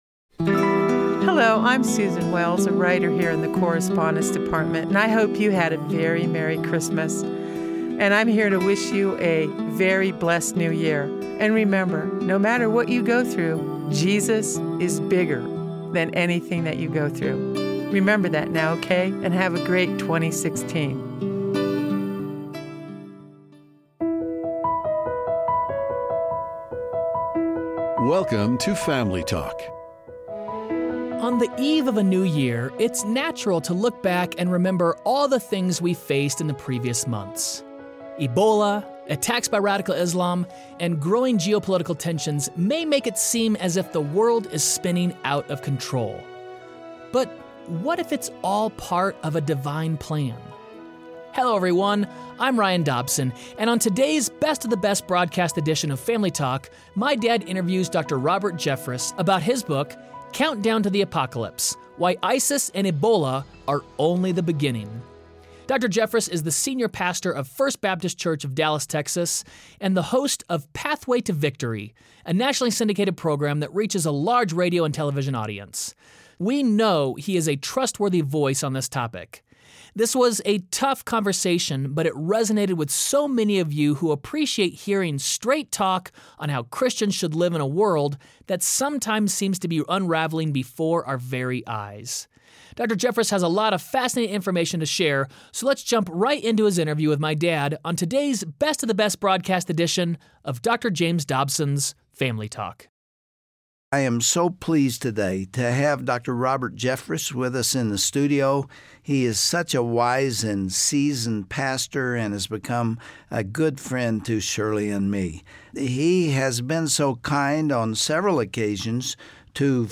But what if this is all part of a divine plan? On the next edition of Family Talk, Dr. James Dobson interviews Dr. Robert Jeffress on the countdown to the Apocalypse.